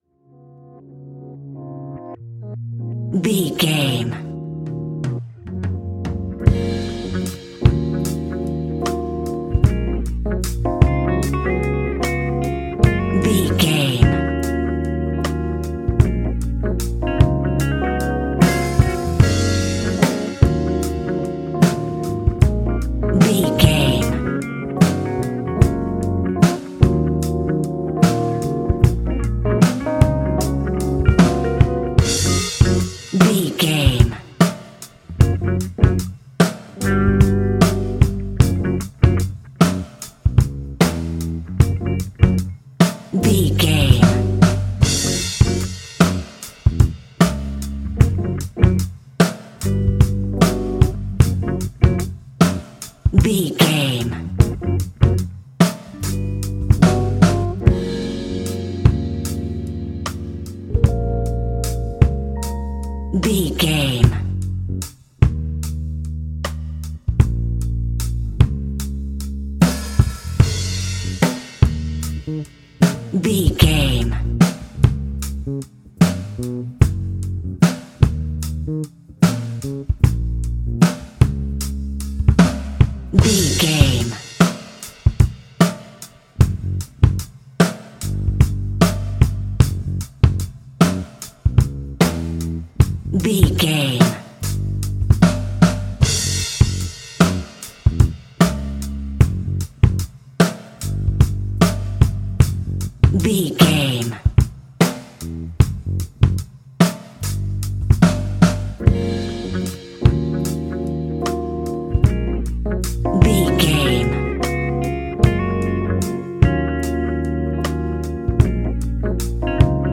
Epic / Action
Fast paced
In-crescendo
Uplifting
Ionian/Major
F♯
hip hop